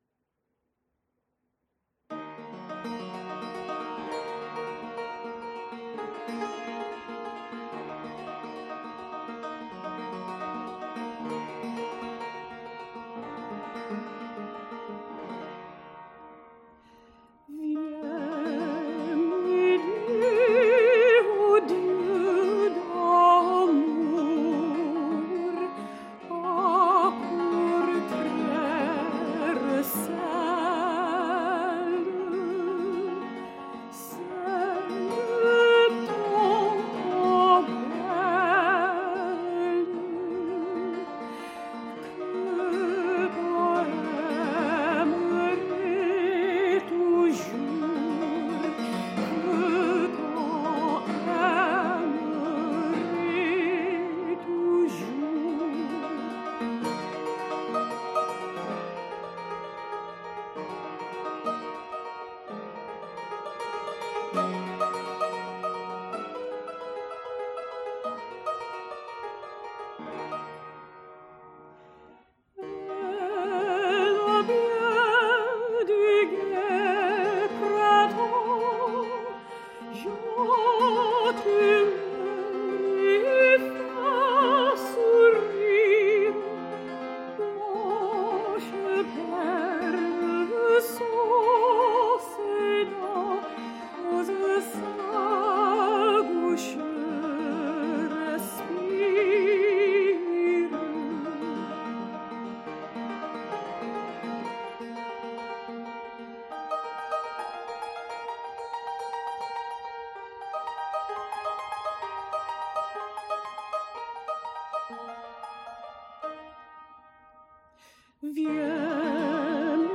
PerformerThe Raritan Players
Subject (lcsh) Romances (Music)